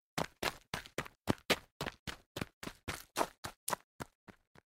Running on Gravel